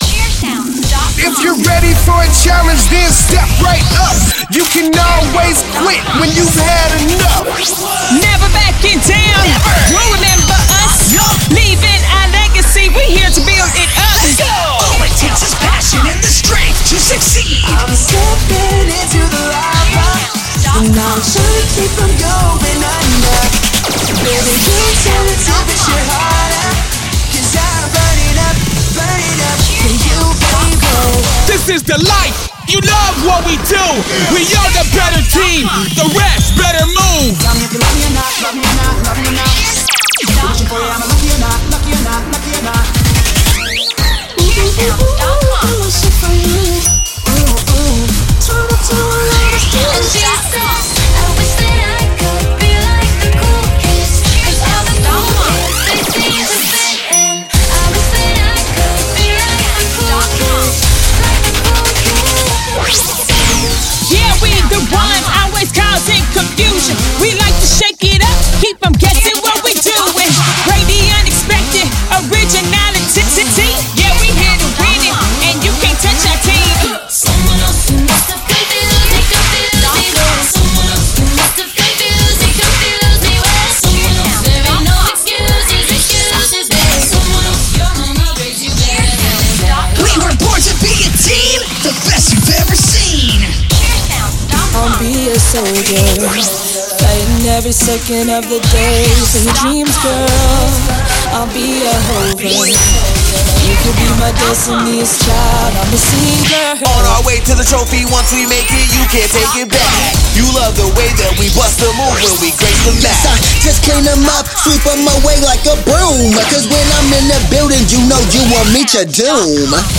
Premade Cheer Music Mix